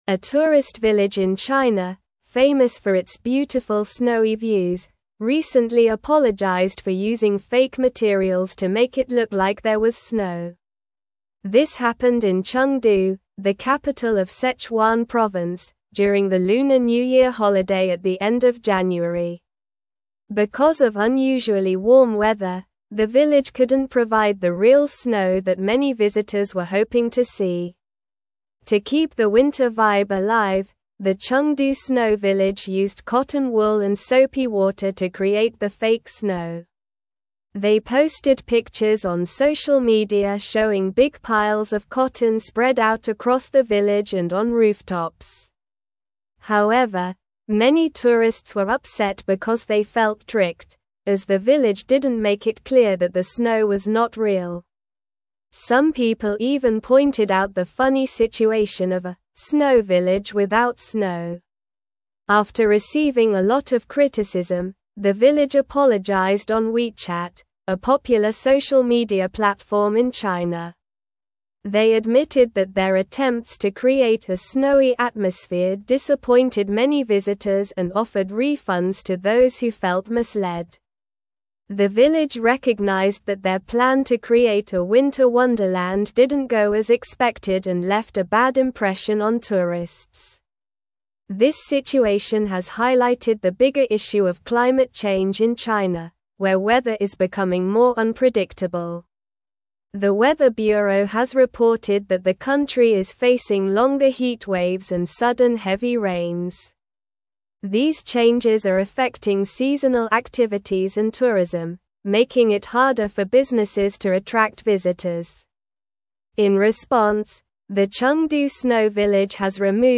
Slow
English-Newsroom-Upper-Intermediate-SLOW-Reading-Chengdu-Village-Apologizes-for-Fake-Snow-Misleading-Tourists.mp3